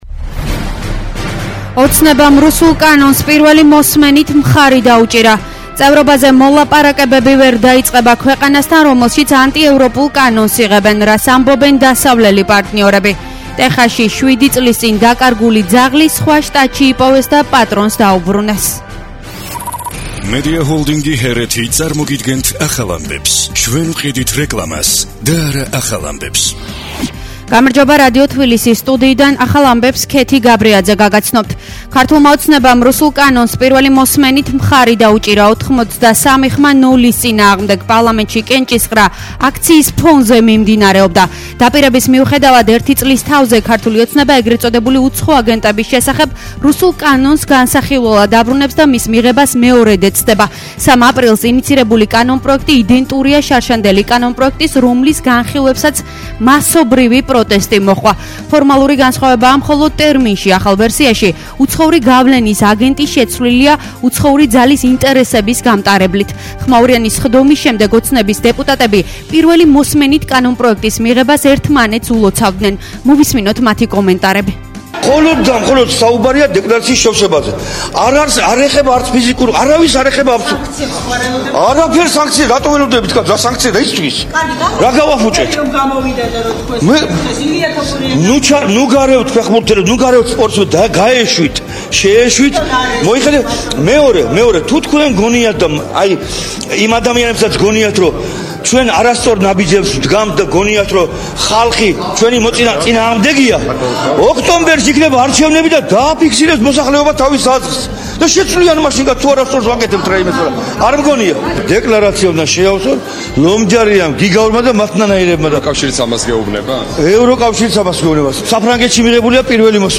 ახალი ამბები 16:00 – 17:00 საათზე